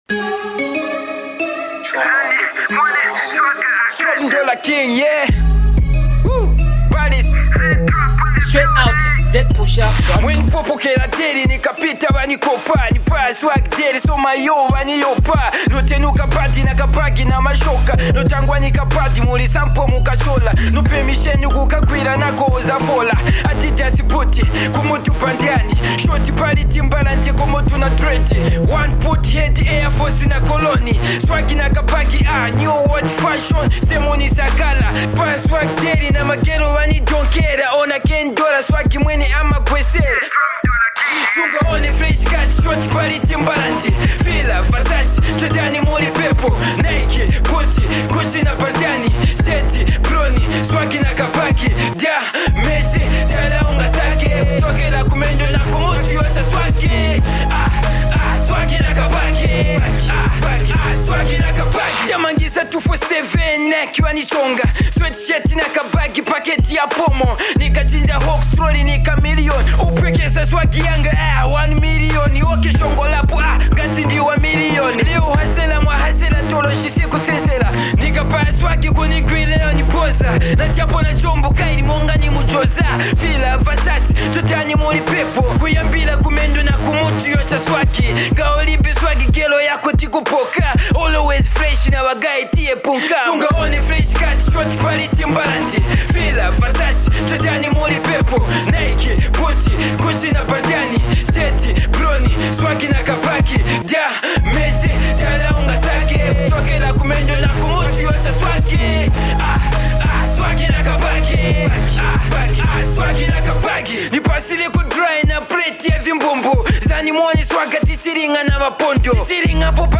Get to download the real hiphop below.